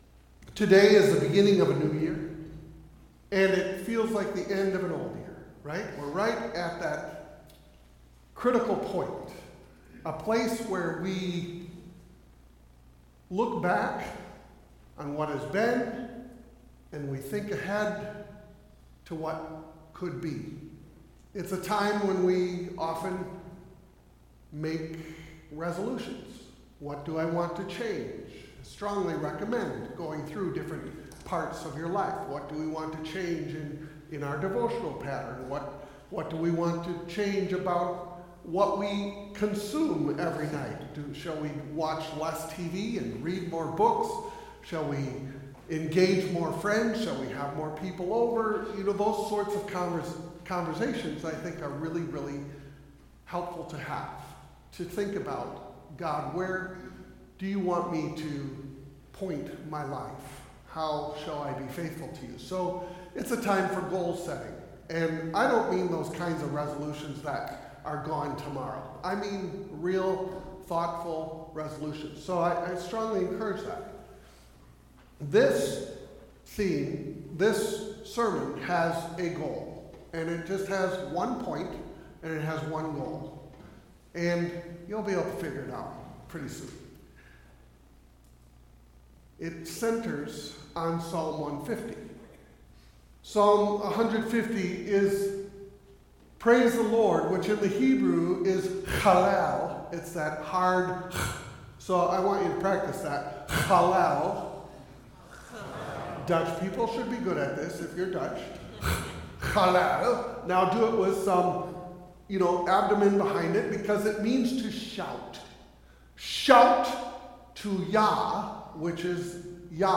Passage: Psalm 150 Service Type: Sunday Service « Meditation 2